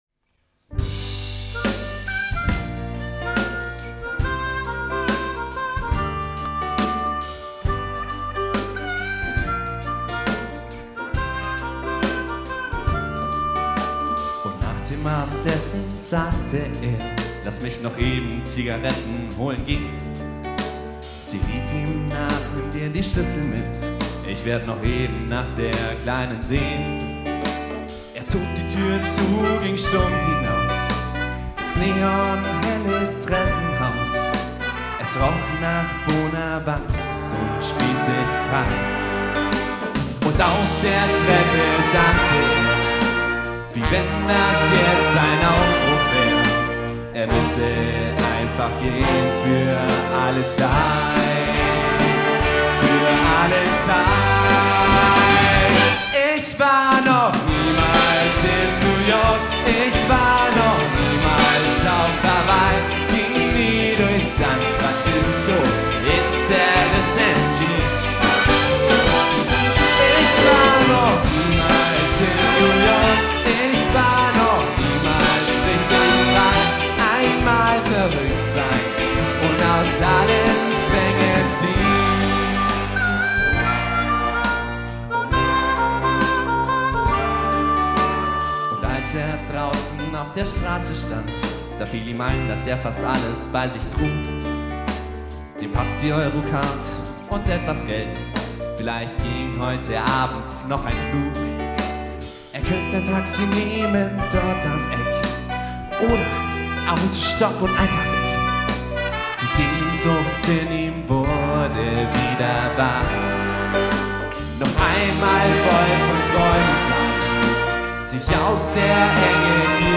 Live Dein Browser kennt leider das audio-tag nicht!
Vocal Bigband (5 sax/3 trpts/3 trbs (G-Dur)